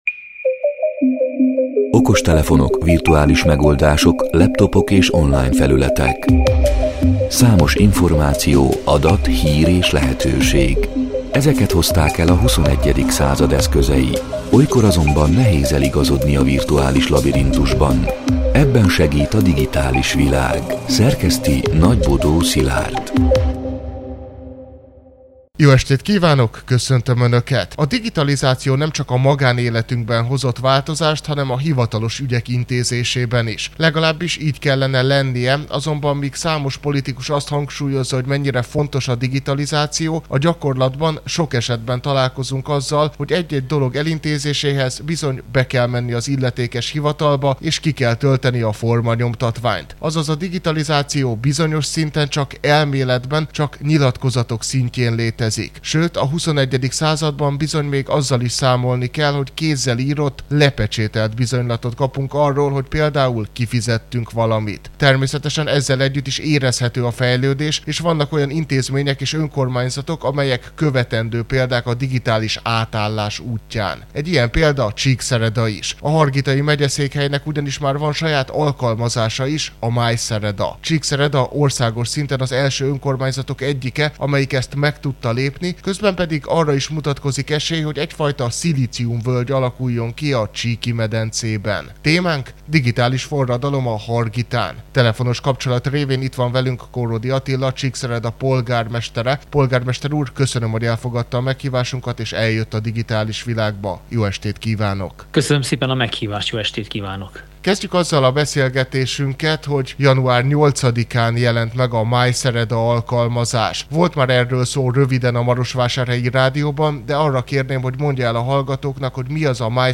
A Marosvásárhelyi Rádió Digitális Világ (elhangzott: 2026. január 27-én, kedden este nyolc órától) c. műsorának hanganyaga: